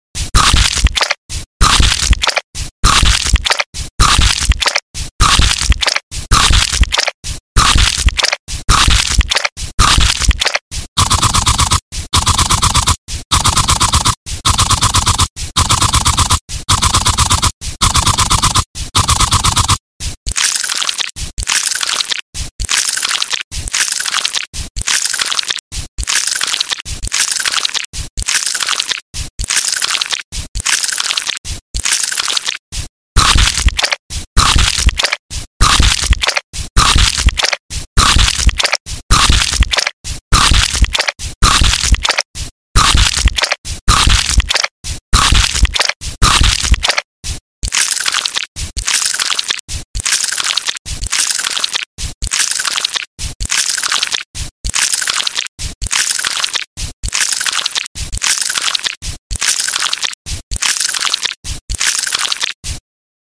ASMR shoulder pampering with spa sound effects free download
ASMR shoulder pampering with spa massage | ASMR arm relaxation